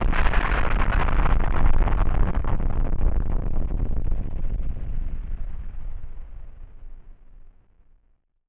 BF_DrumBombB-03.wav